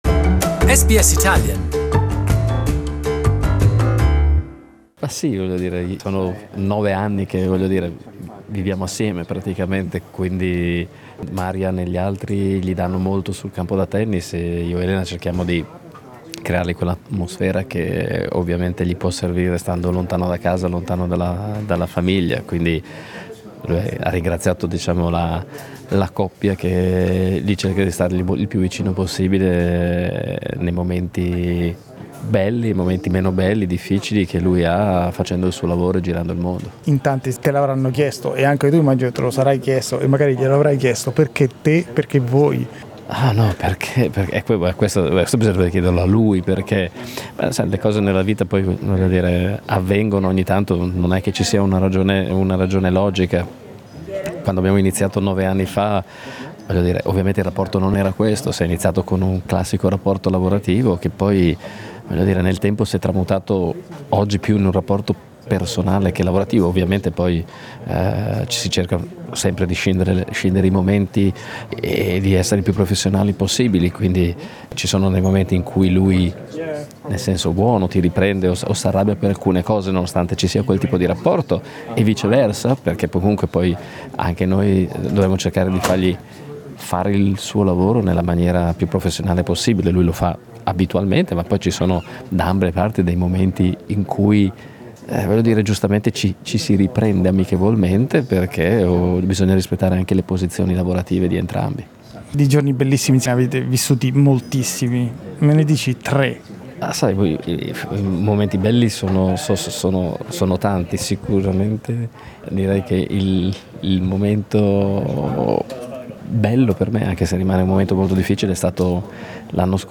In this exclusive interview he tells SBS Italian about his enduring partnership with the tennis legend.